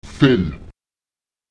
Lautsprecher vel [fEl] liegen